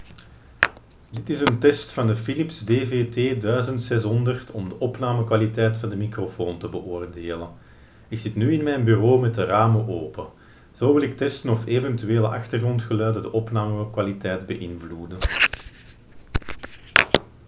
First in a closed room, then in the same room but with the windows open, and then from a meter away from the microphone.
Audio fragment 2 (window open)
The pen captures our voice well, although it is advisable to keep the recorder nearby.